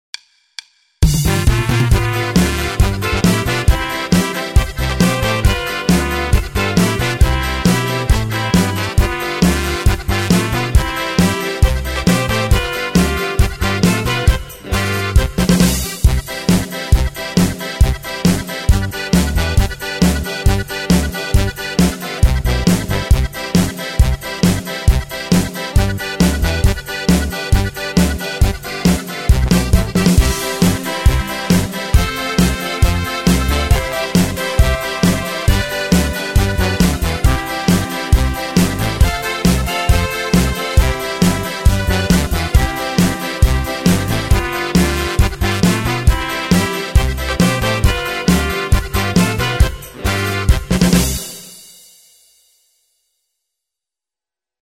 Steir Polka 2
steir polka 2 demo.mp3